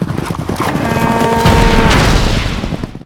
bullcharge.ogg